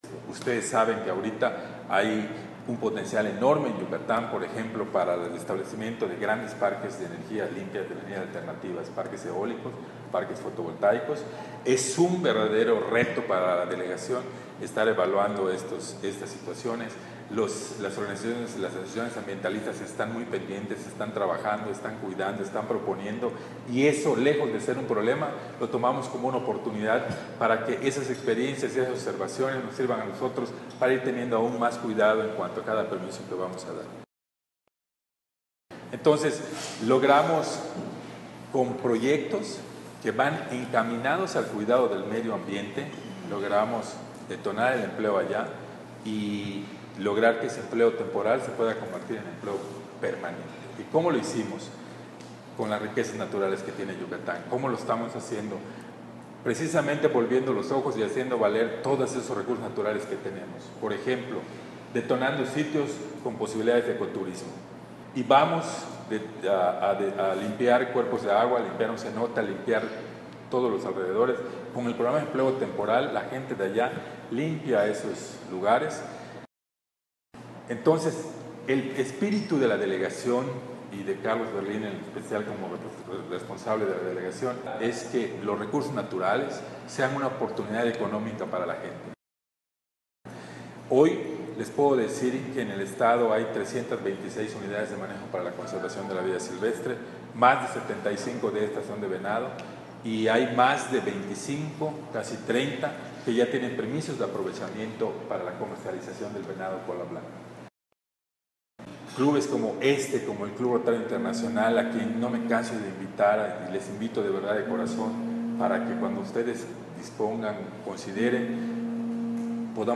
Mérida,Yucatán 11 de Octubre 2016.- A invitación de los socios del Club Rotario Mérida Montejo, el delegado federal de la Secretaría de Medio Ambiente y Recursos Federales (Semarnat) en Yucatán, MVZ. Carlos Berlín Montero, acudió a presentarles su visión en torno al quehacer que desempeña al frente de la delegación, así como las diversas oportunidades de desarrollo, desde los grandes parques eólicos y fotovoltaicos que atraen grandes inversiones, hasta el impulso a programas sociales que desde Semarnat se han estado trabajando a lo largo y ancho de todo el Estado.